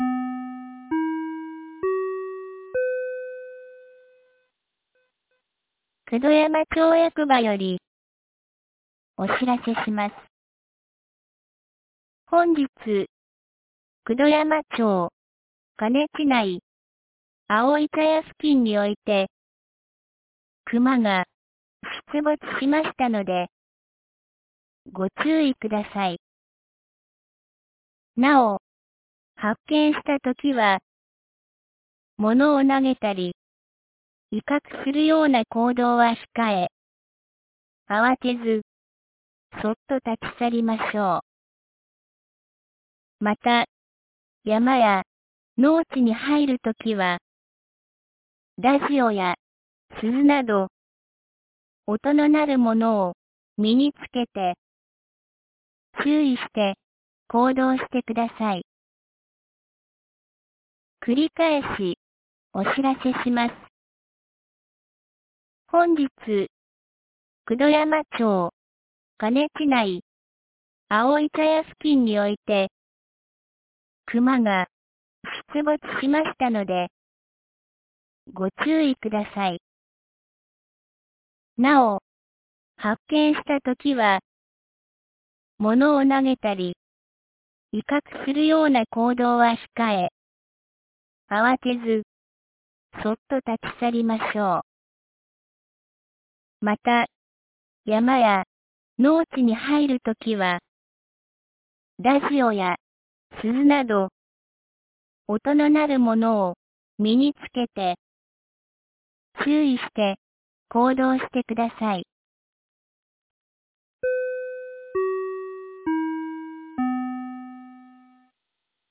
2025年04月30日 14時02分に、九度山町より河根地区、丹生川地区へ放送がありました。
放送音声